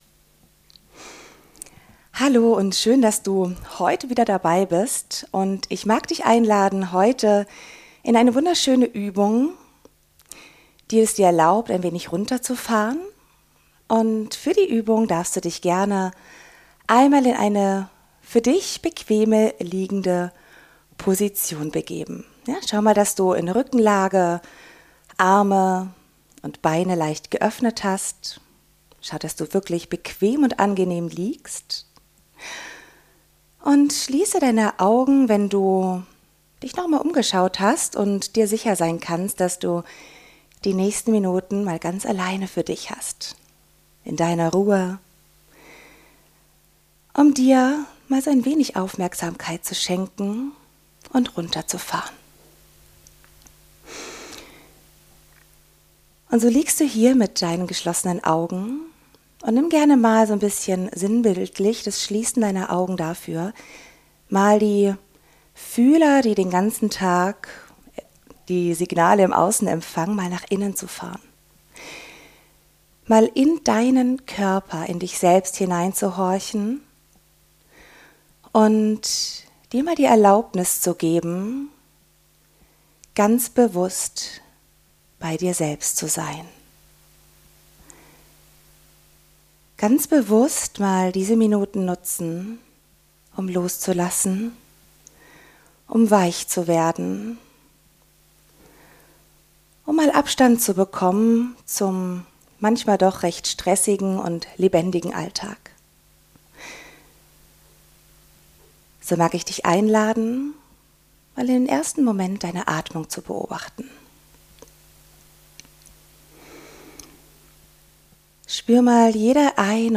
In dieser besonderen Episode unseres FIT TEAM Podcasts erwartet euch erneut kein klassisches Podcast-Gespräch, sondern eine geführte Körperreise zur bewussten Entspannung und Stressreduktion.
In dieser Sonderfolge werdet ihr Schritt für Schritt eingeladen, die Aufmerksamkeit durch den eigenen Körper wandern zu lassen – ähnlich einem Body Scan – um Spannungen wahrzunehmen und gezielt loszulassen. Die ruhige und kompakte Entspannungsübung unterstützt dabei, Stress abzubauen, innere Unruhe zu reduzieren und Körper sowie Geist wieder in Balance zu bringen.